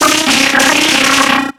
Cri de Noadkoko dans Pokémon X et Y.